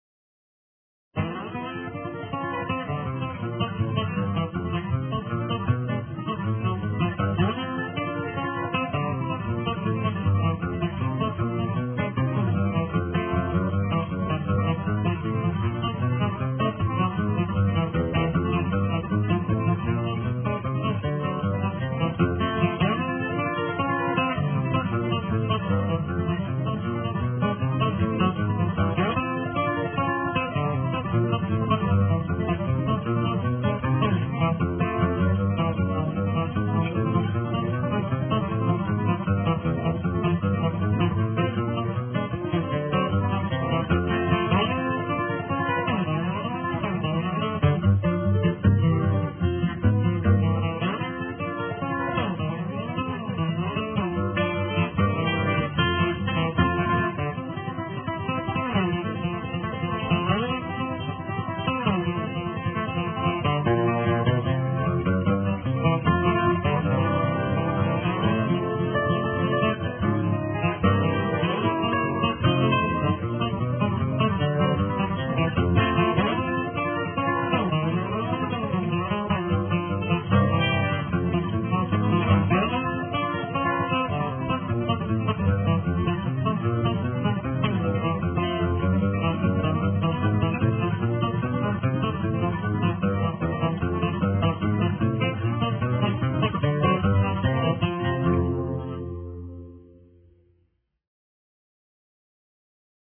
six steel strings, his fingers, and a thumbpick